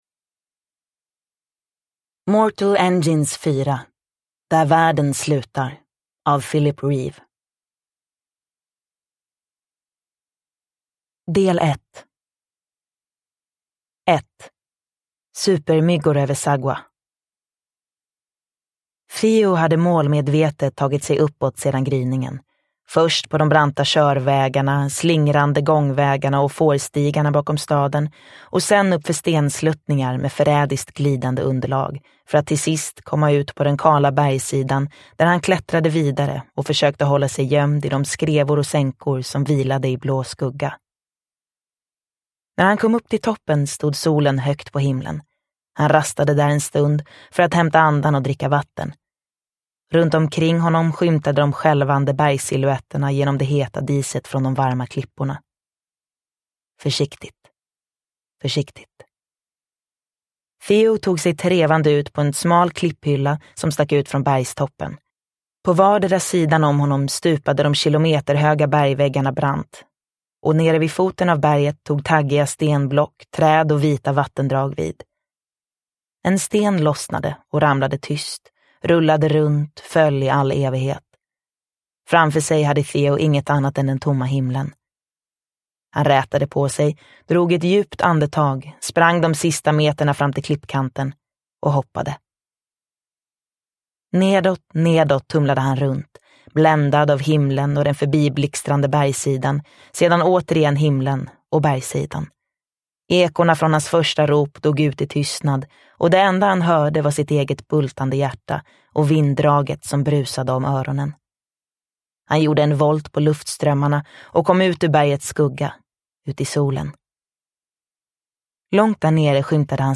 Där världen slutar – Ljudbok – Laddas ner